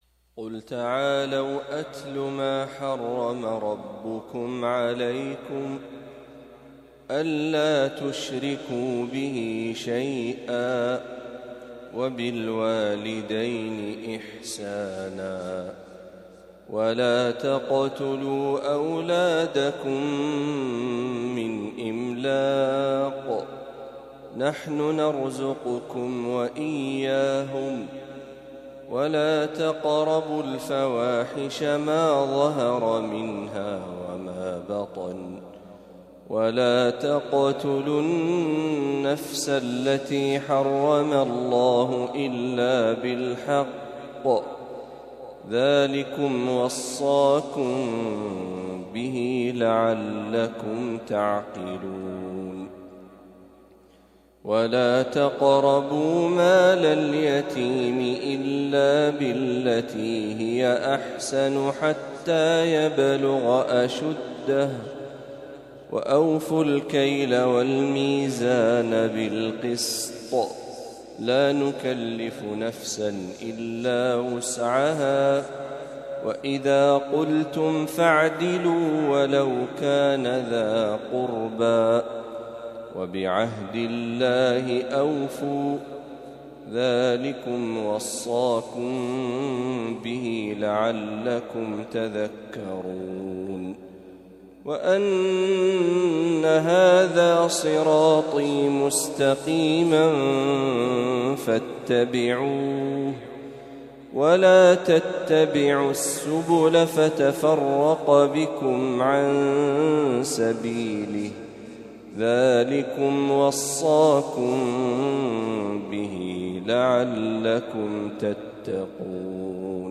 ما تيسر من سورة الأنعام | فجر الأحد ٢٦ ربيع الأول ١٤٤٦هـ > 1446هـ > تلاوات الشيخ محمد برهجي > المزيد - تلاوات الحرمين